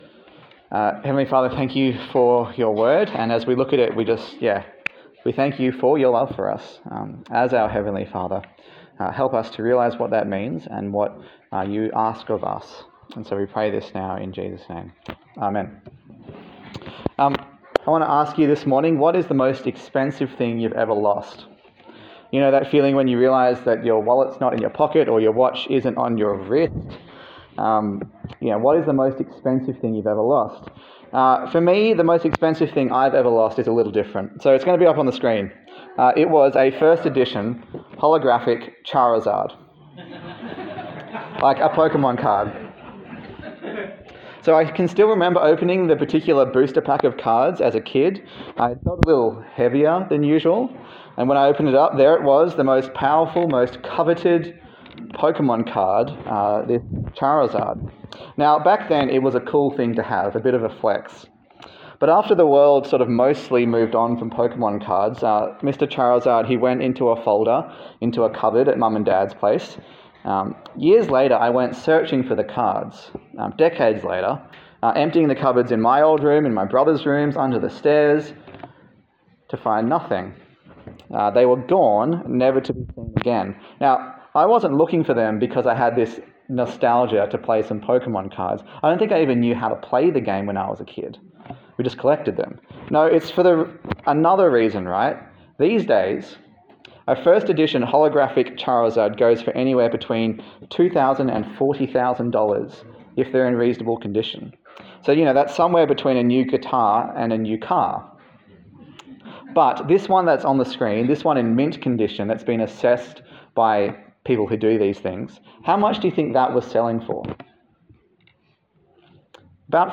Luke Passage: Luke 15 Service Type: Sunday Service